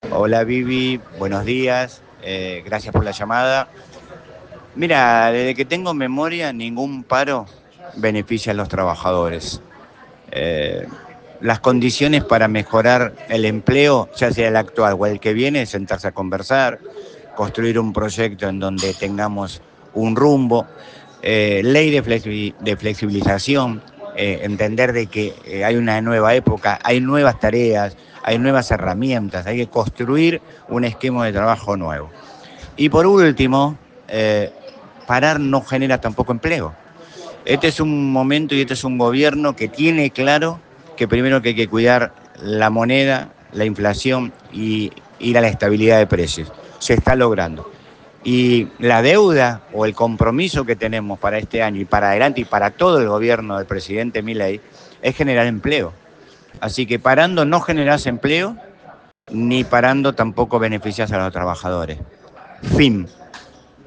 Desde Argentina Política, conversamos con diferentes actores afines al gobierno Nacional.
Marcelo Ballester, concejal electo año 2023/2027